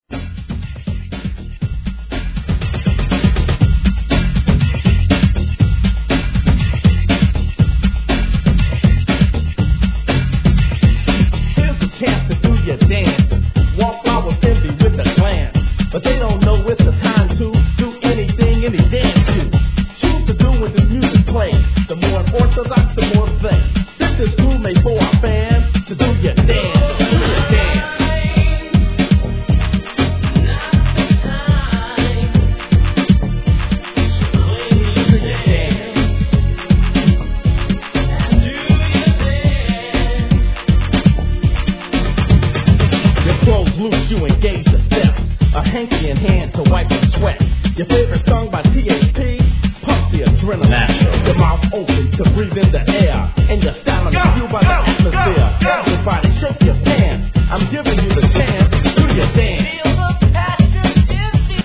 HIP HOP/R&B
1991年、マイナー盤ダンス〜HIP HOP!!